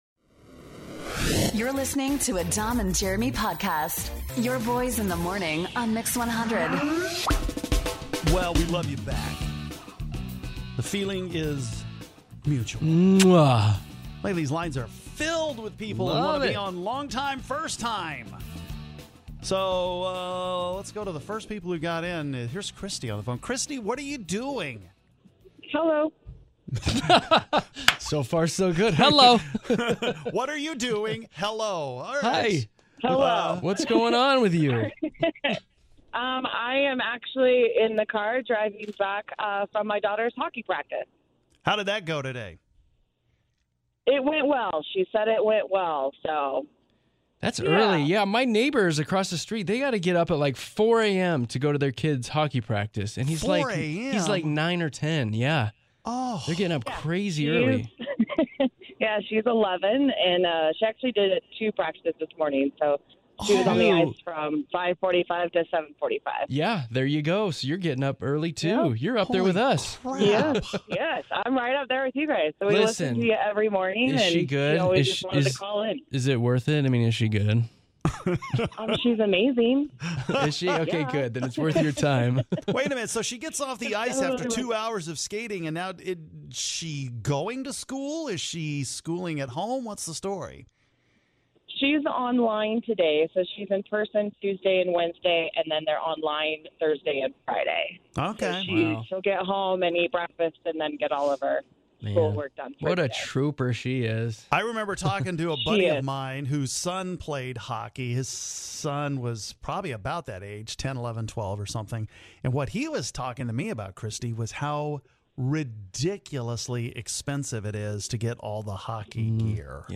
We talk to our LONG time listeners here after they decide to finally call in for the first time!